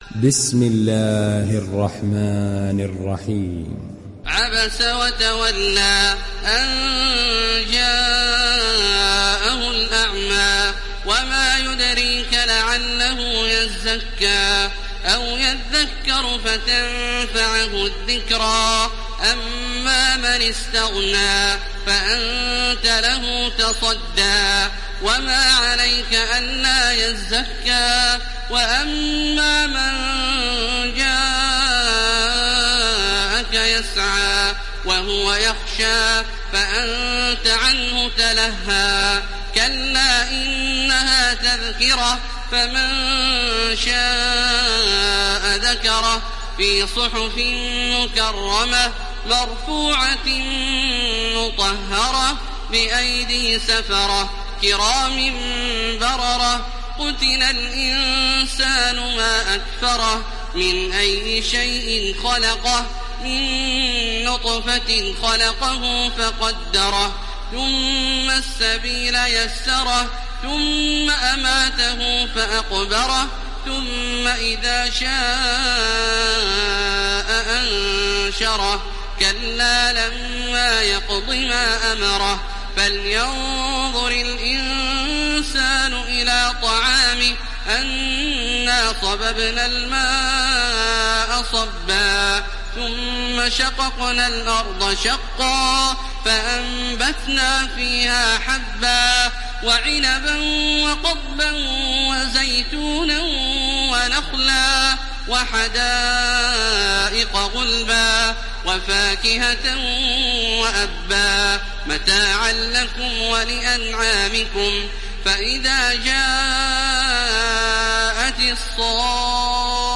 Surah Abasa Download mp3 Taraweeh Makkah 1430 Riwayat Hafs from Asim, Download Quran and listen mp3 full direct links
Download Surah Abasa Taraweeh Makkah 1430